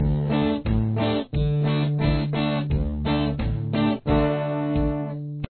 Creating Drop D Riffs
10. Here’s a moving bass line (low notes) with a moving melody (high E string).